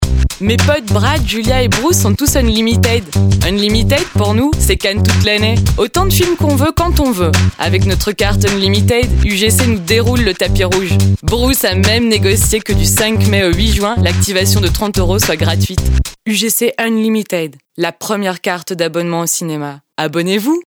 voix grave sensuelle et douce
Kein Dialekt
deep, sensual and sweet voice